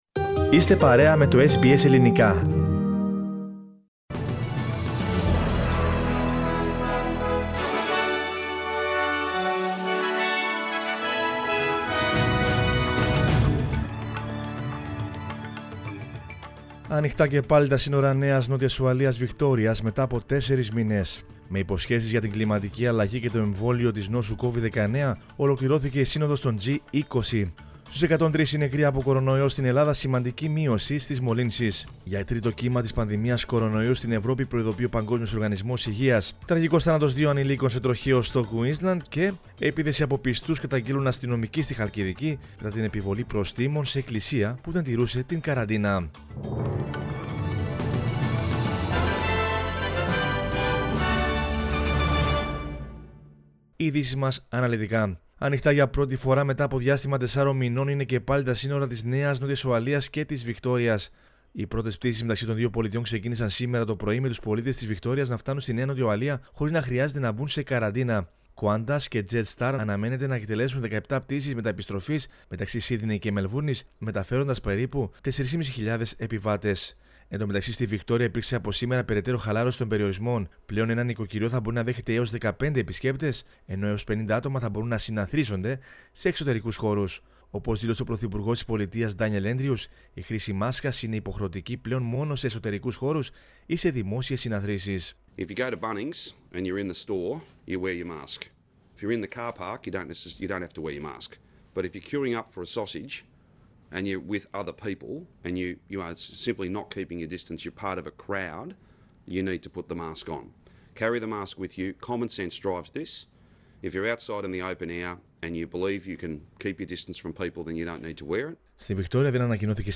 Δελτίο Ειδήσεων 23.11.20